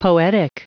Prononciation du mot poetic en anglais (fichier audio)
Prononciation du mot : poetic